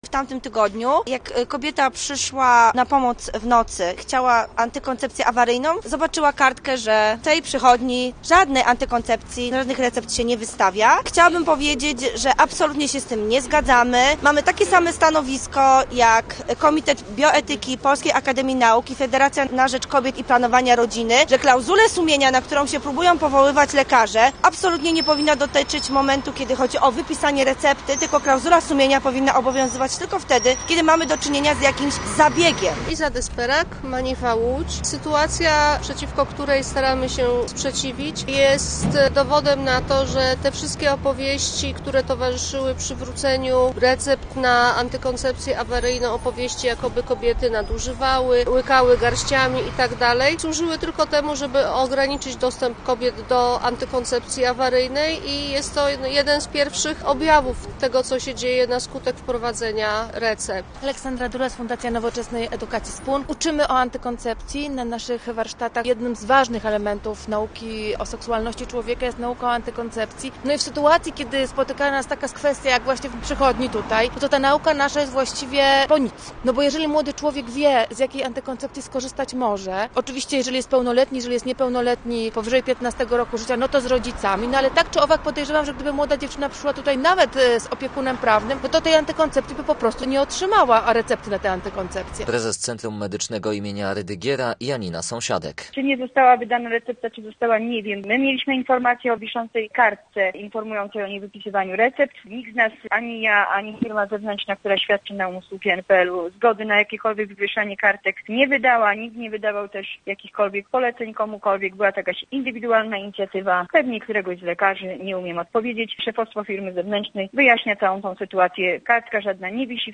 Posłuchaj relacji naszego reportera i dowiedz się więcej: Nazwa Plik Autor Koalicja TAK o tzw.